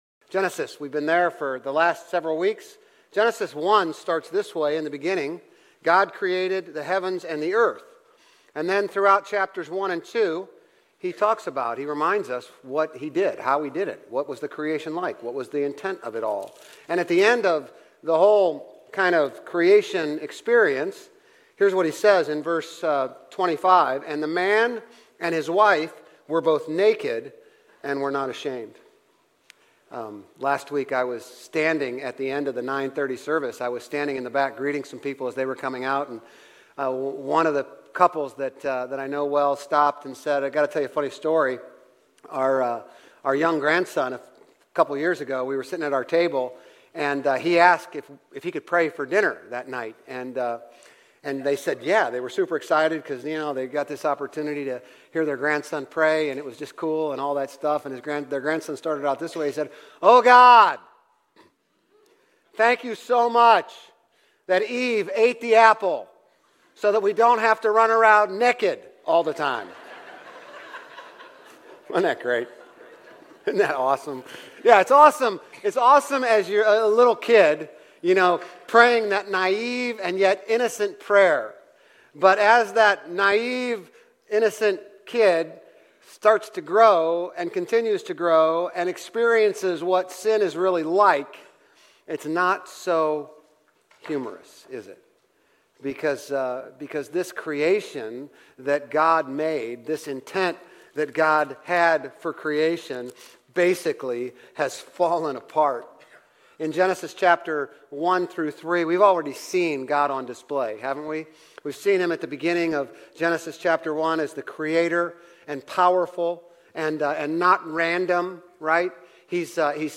Grace Community Church Old Jacksonville Campus Sermons Genesis - Sin and the Fall Sep 23 2024 | 00:37:02 1x 00:00 / 00:37:02 Subscribe Share RSS Feed Share Link Embed